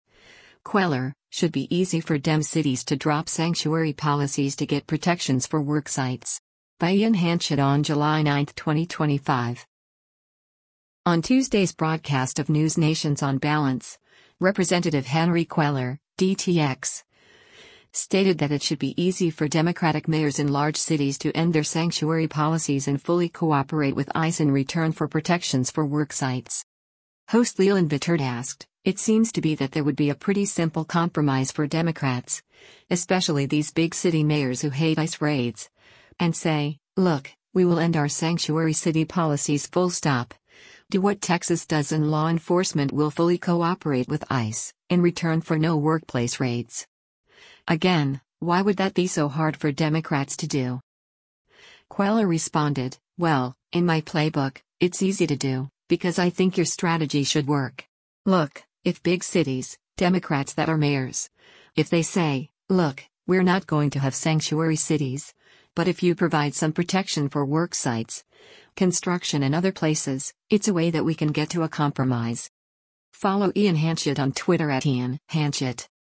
On Tuesday’s broadcast of NewsNation’s “On Balance,” Rep. Henry Cuellar (D-TX) stated that it should be easy for Democratic mayors in large cities to end their sanctuary policies and fully cooperate with ICE in return for protections for worksites.